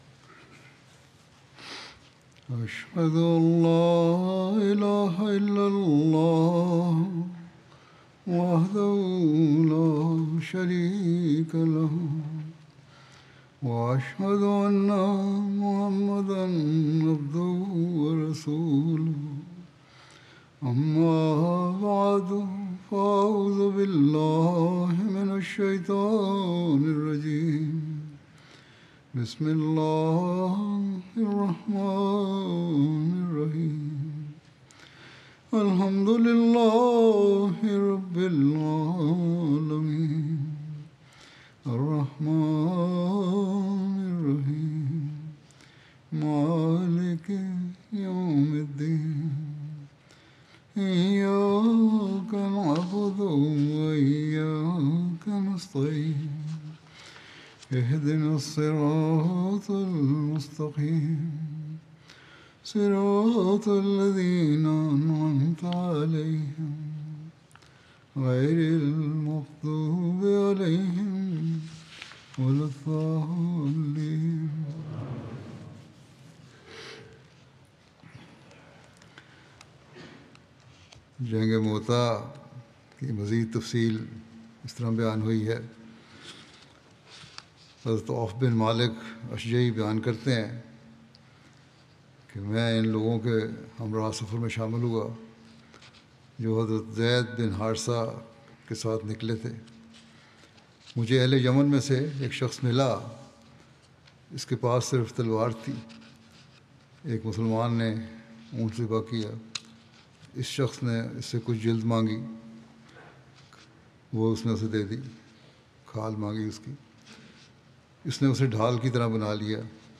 Urdu Friday Sermon by Head of Ahmadiyya Muslim Community
Urdu Friday Sermon delivered by Khalifatul Masih